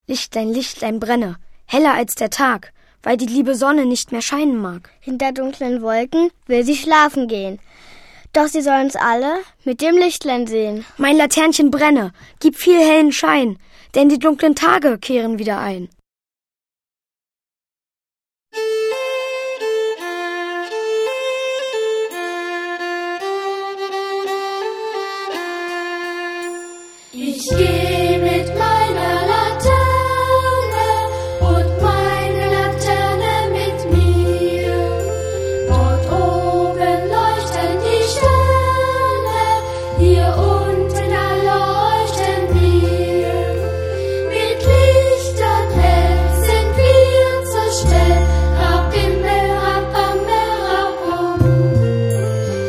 Lieder, Gedichte und Geschichten
Hier singen und erzählen fröhliche Laternen-Kinder
Traditionelle Weisen